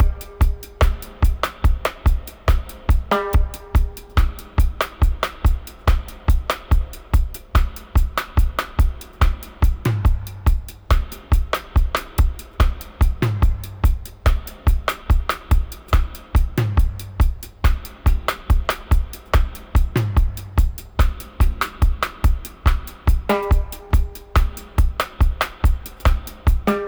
142-FX-03.wav